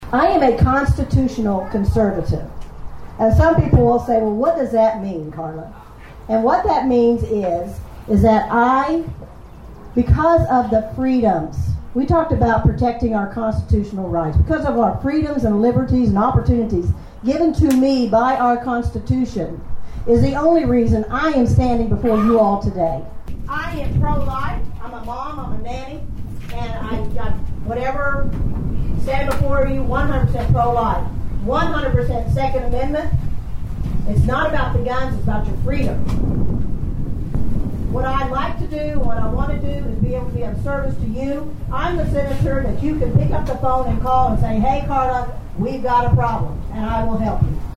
A Republican Meet And Greet was held Saturday in the Thayer City park for candidates running for office in the August Election.
The Meet and Greet began with each candidate making a brief statement.